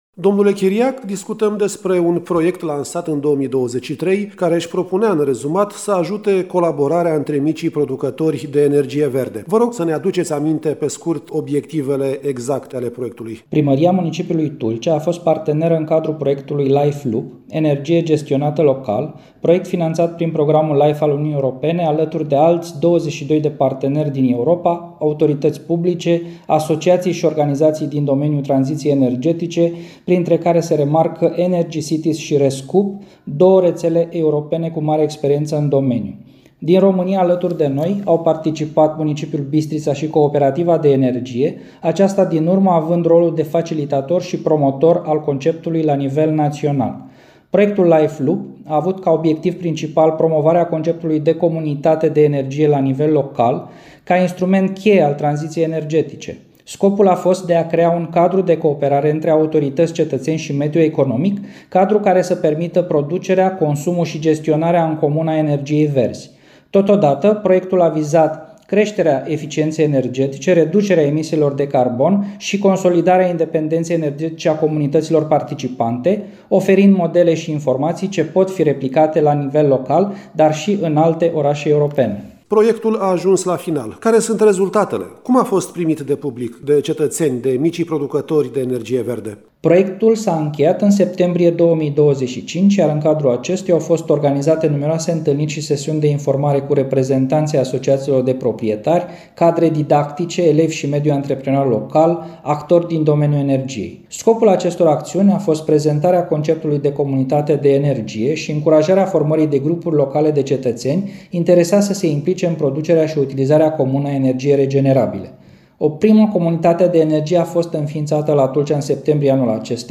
Detalii, în interviul următor